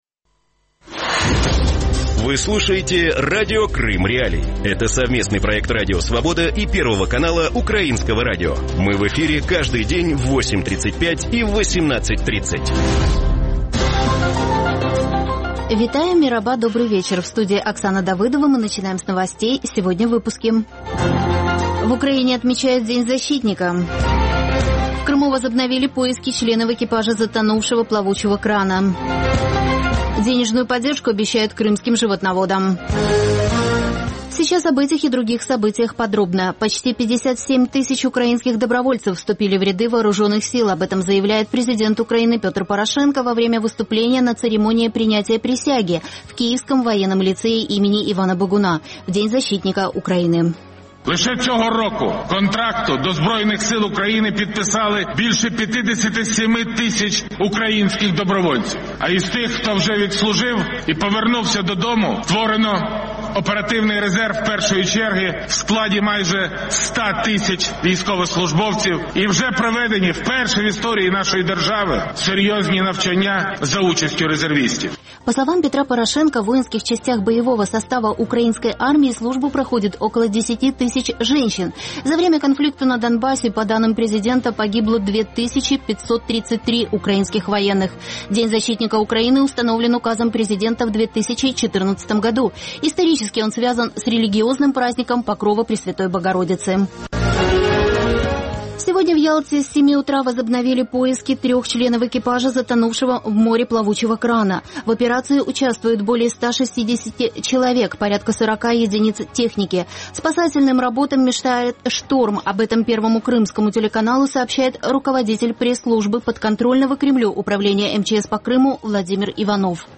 Вечірній ефір новин про події в Криму.